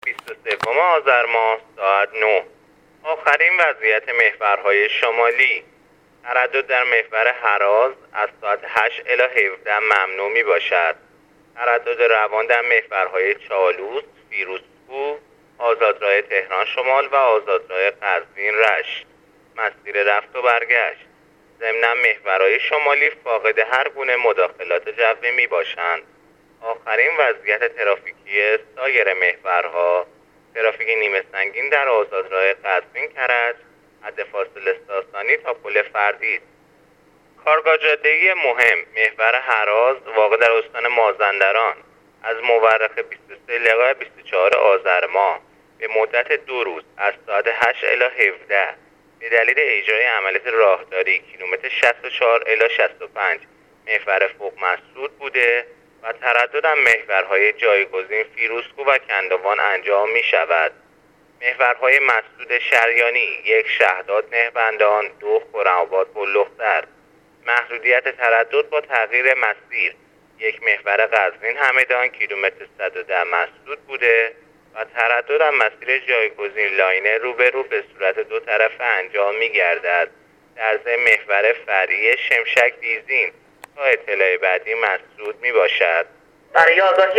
گزارش رادیو اینترنتی از وضعیت ترافیکی جاده‌ها تا ساعت ۹ بیست و سوم آذر